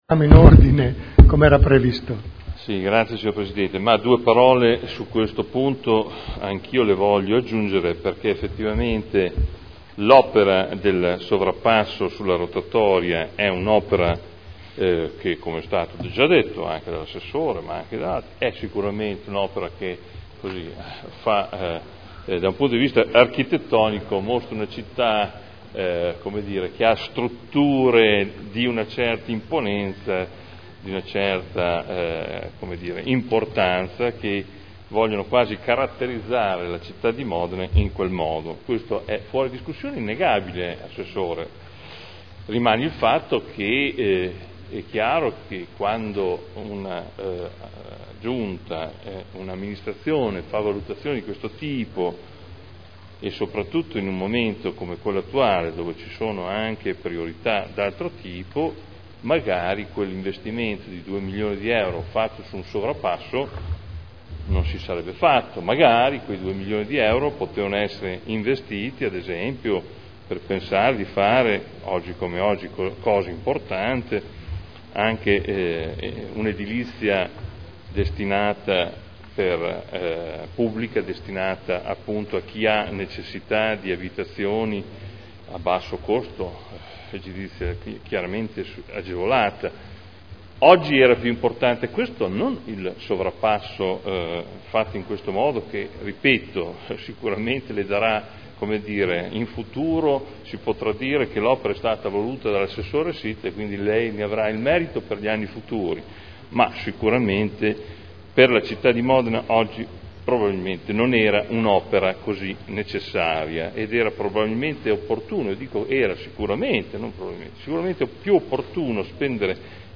Seduta del 12/09/2011.